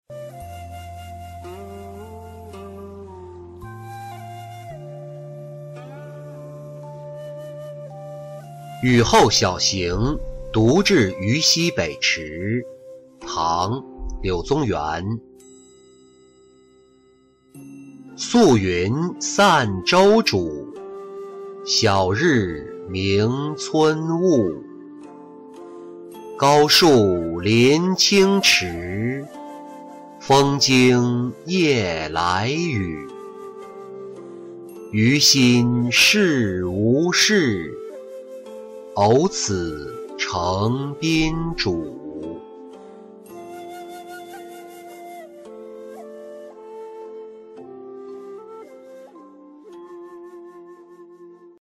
雨后晓行独至愚溪北池-音频朗读